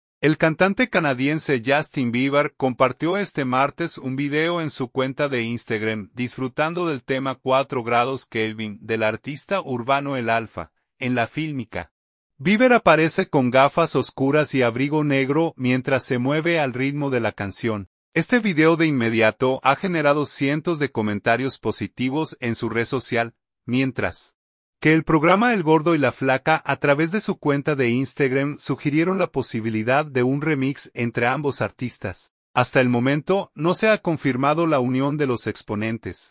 artista urbano